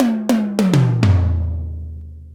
102 BPM Beat Loops Download